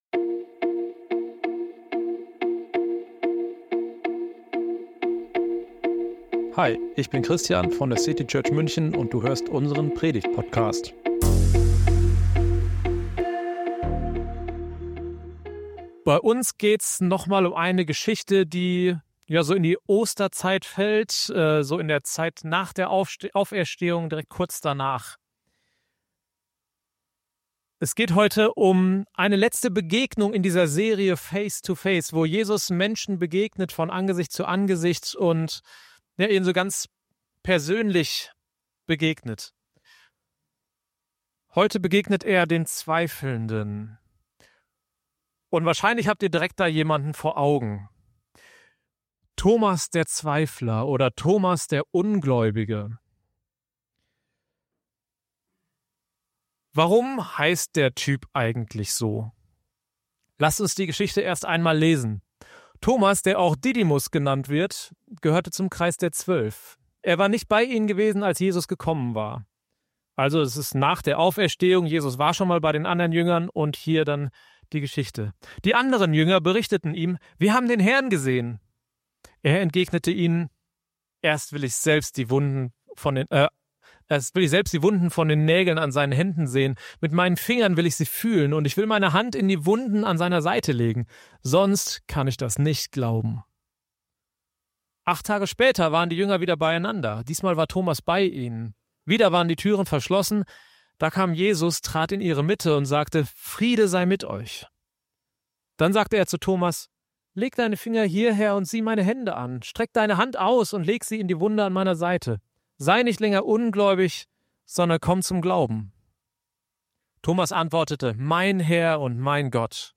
Mit dieser Predigt findet unsere Reihe Face2Face ihren Abschluss.